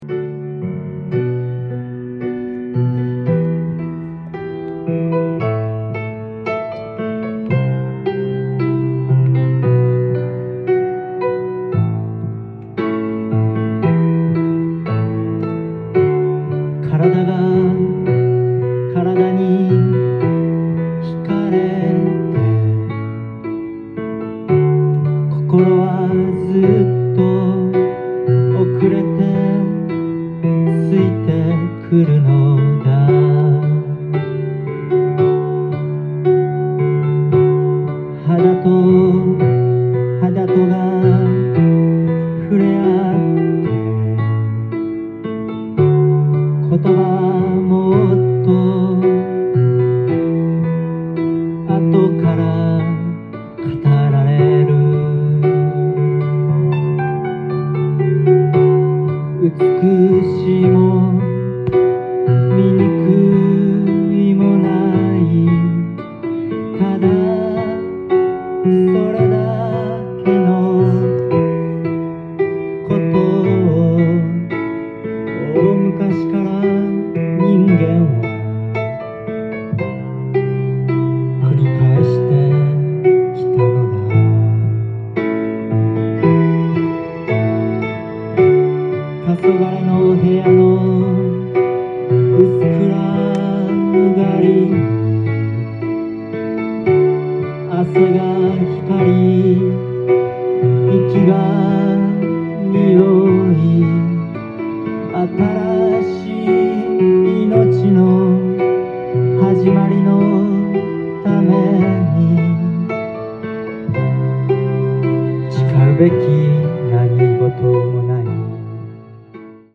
ジャンル：FOLKSONG
店頭で録音した音源の為、多少の外部音や音質の悪さはございますが、サンプルとしてご視聴ください。